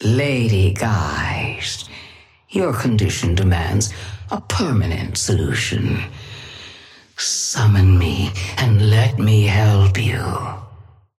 Sapphire Flame voice line - Lady Geist, your condition demands a permanent solution, summon me and let me help you.
Patron_female_ally_ghost_start_02.mp3